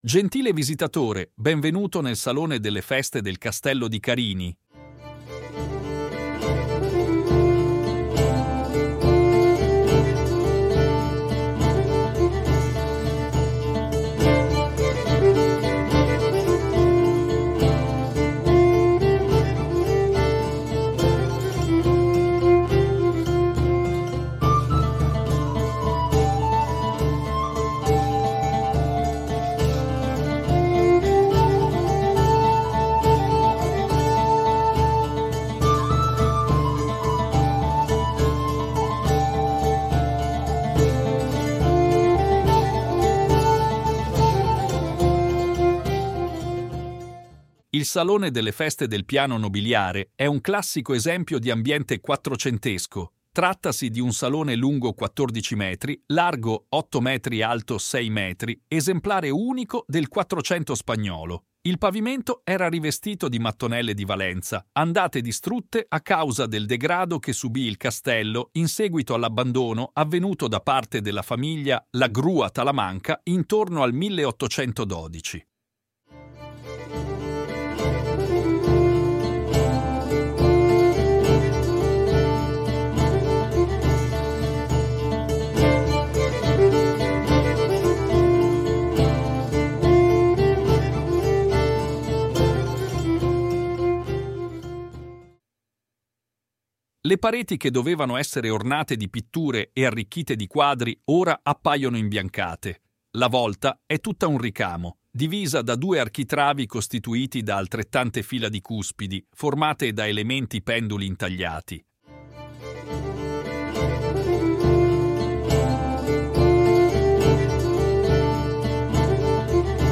Audio Guide